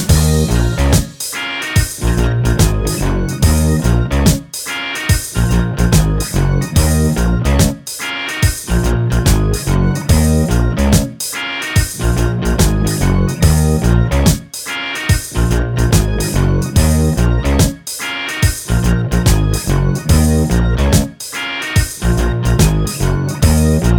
Minus Main Guitar Pop (1980s) 4:01 Buy £1.50